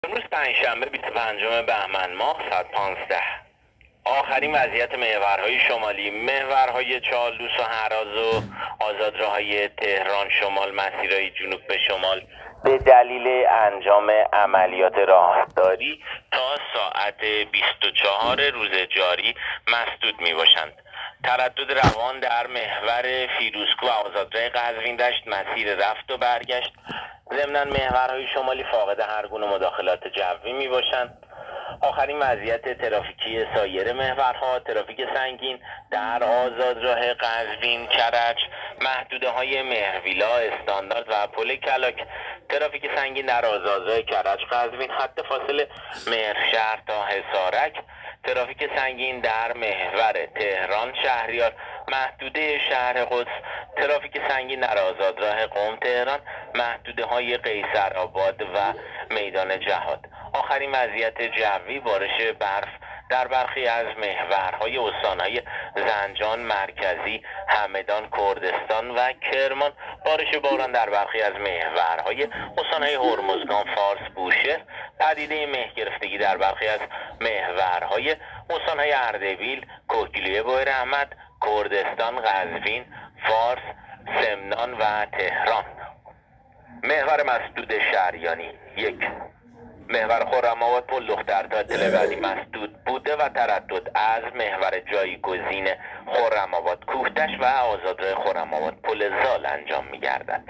گزارش رادیو اینترنتی از آخرین وضعیت ترافیکی جاده‌ها ساعت ۱۵ بیست و پنجم بهمن؛